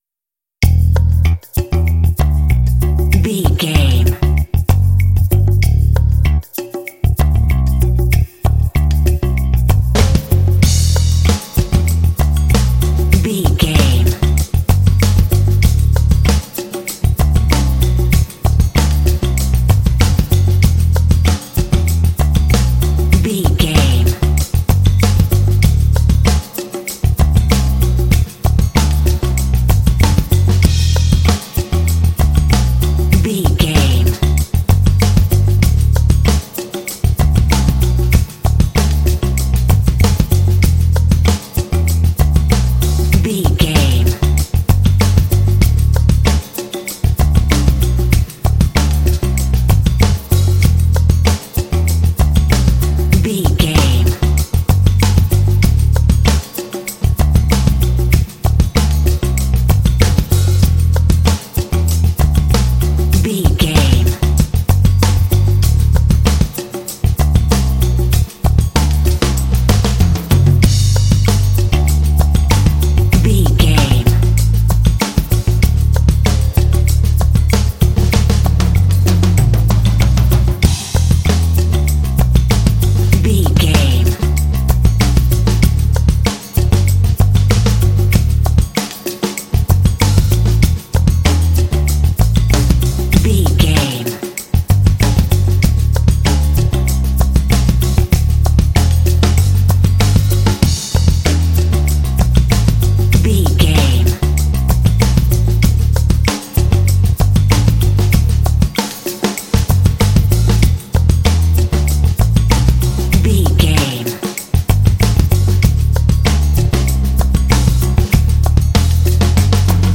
Aeolian/Minor
sultry
groovy
bass guitar
drums
percussion
Funk
smooth